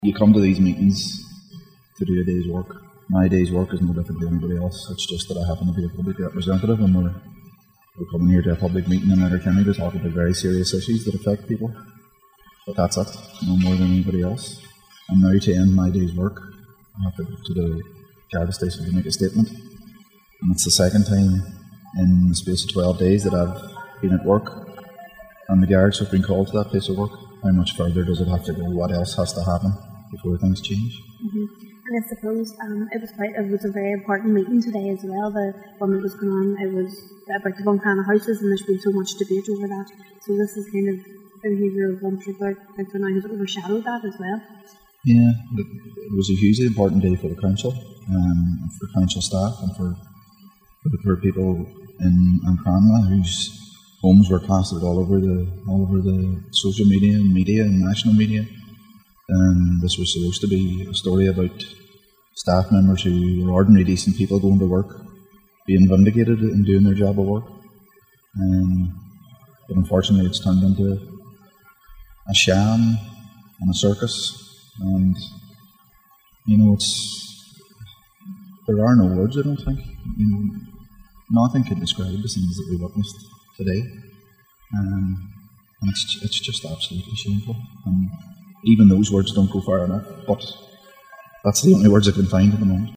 He told Highland Radio News no-one should have to see gardai called to their place of work………..